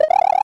smw_spinjump.wav